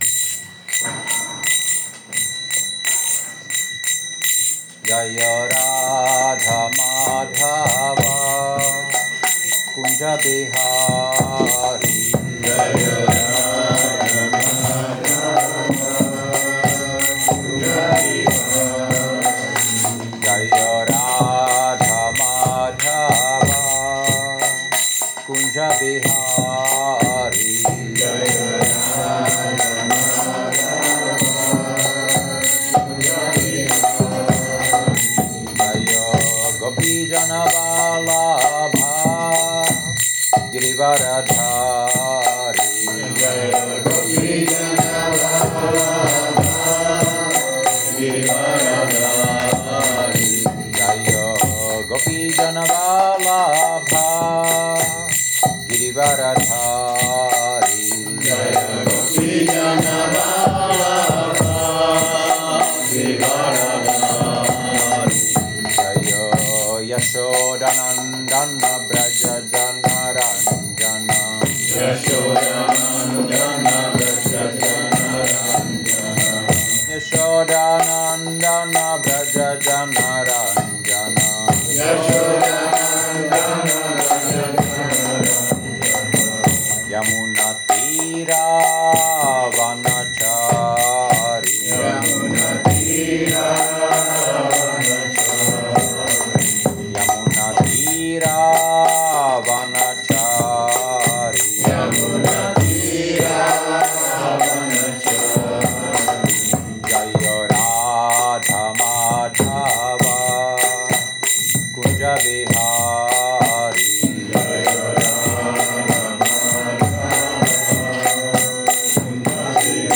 Přednáška SB-4.21.37